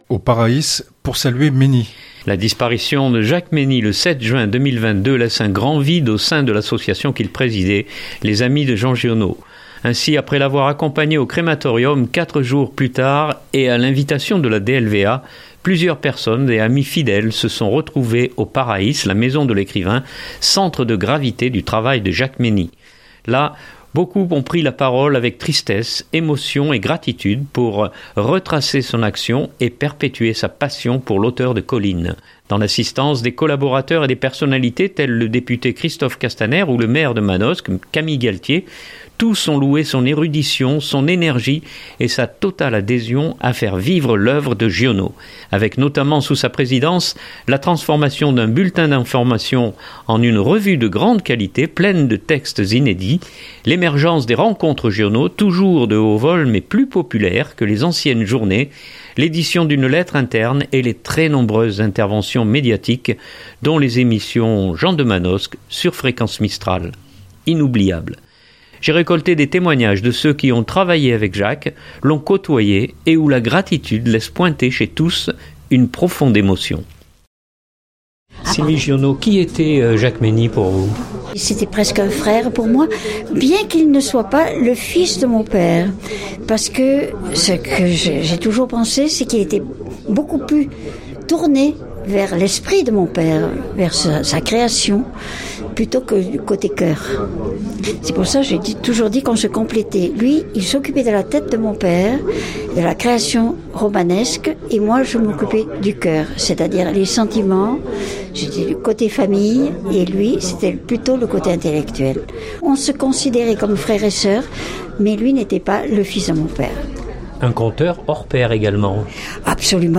Là, beaucoup ont pris la parole avec tristesse, émotion et gratitude pour retracer son action et perpétuer sa passion pour l’auteur de Colline.